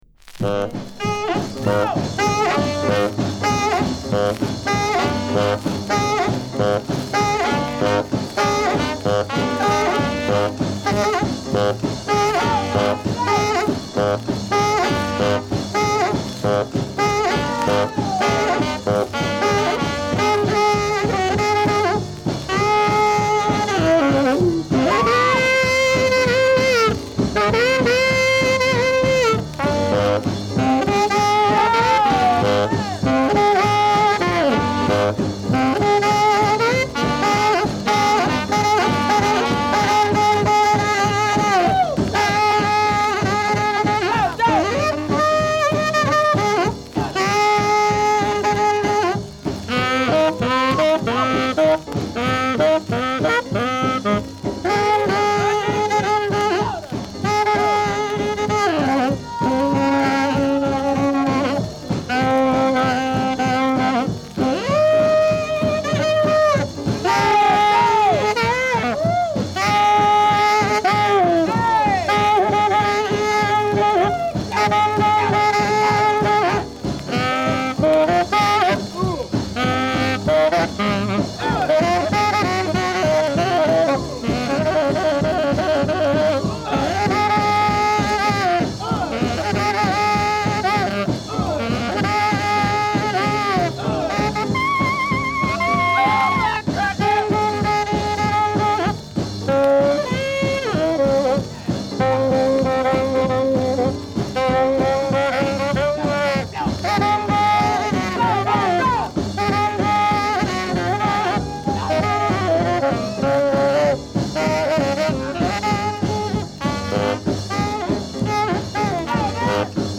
LAを代表するR&Bテナー・サックス奏者。
ロウな録音でラフにぶっ飛ばす様が痛快。
[Jivers/Boppers/Strollers] [Comped] [NEW]